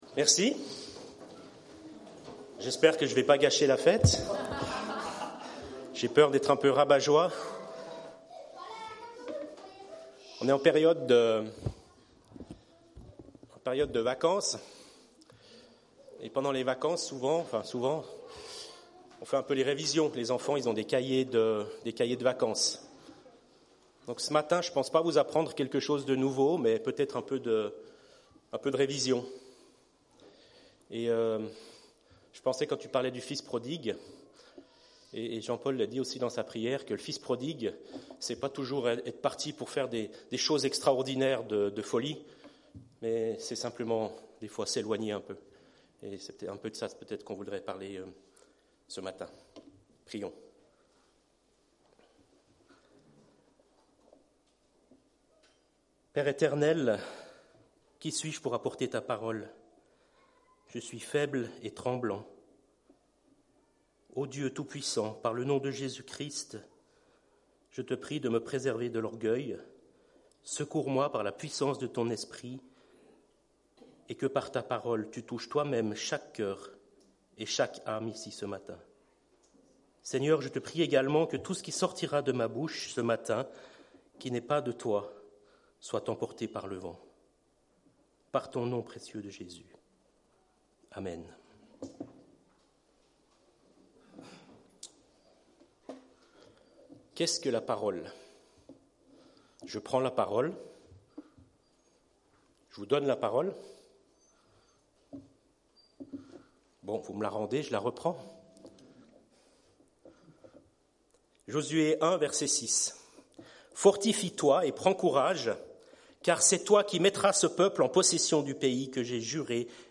Culte du 27 juillet